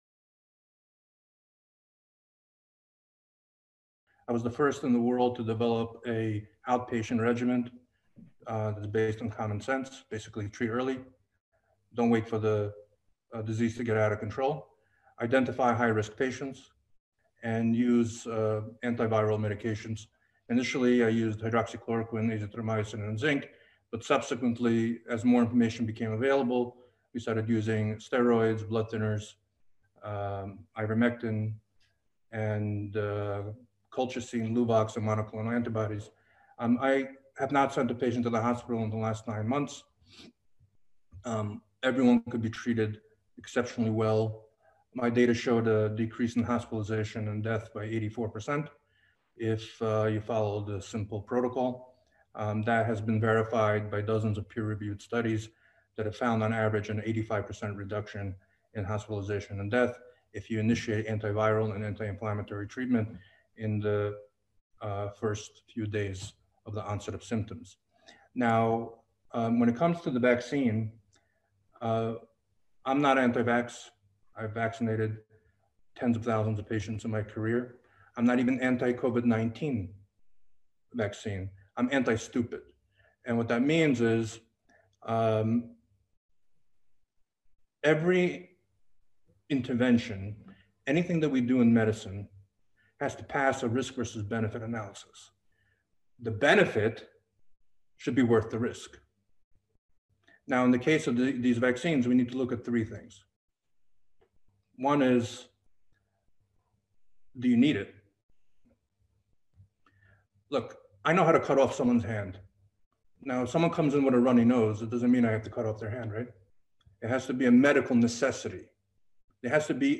בית הדין ברוקלין: ד"ר ולדימיר זלנקו (חלק א') רופא משפחה בכיר מפתח "פרוטוקול זלנקו קוביד-19" מעיד בפני בית הדין על נזקי החיסונים בהשוואה לנזקי קוביד-19
ליקטנו עבורכם קטעים חשובים מתוך העדויות שנמסרו לבית הדין ע"י המומחים באסיפה שנערכה בתאריך 21.11.2021.